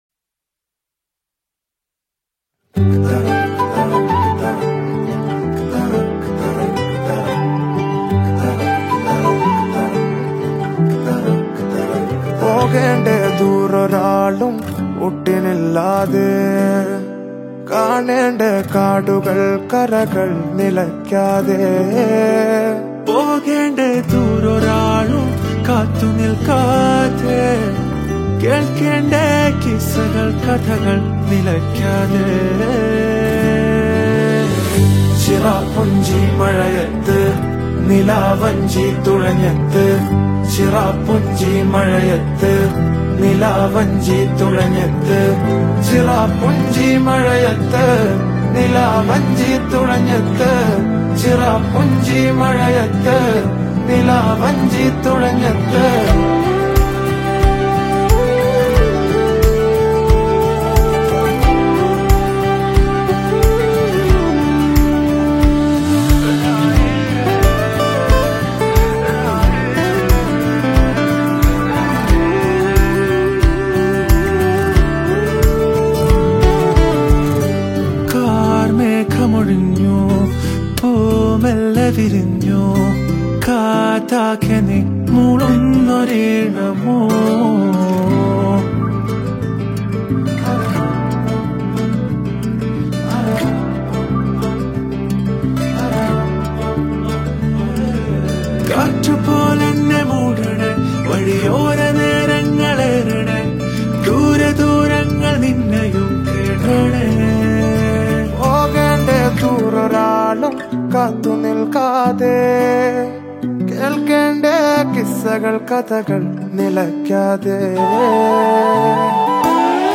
a poetic, emotional indie song